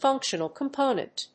意味・対訳 ファンクショナル・コンポーネント